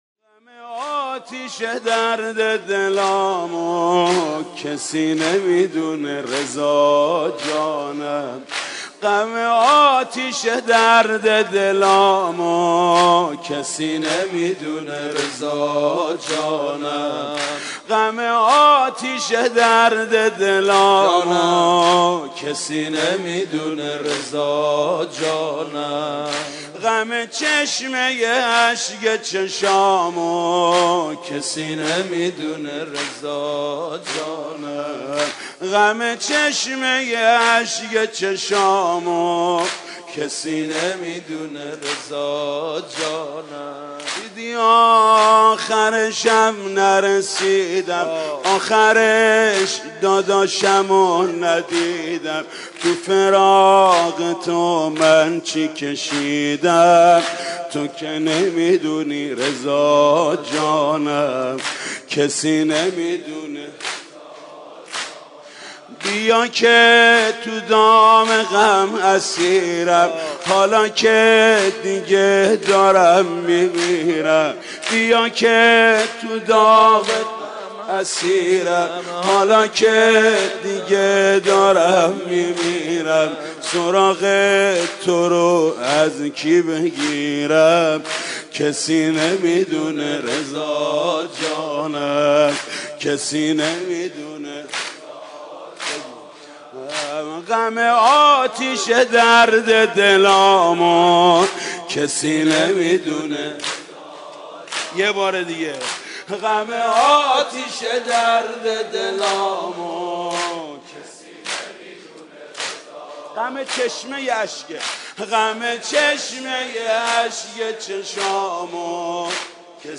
ویژه شهادت حضرت معصومه (واحد)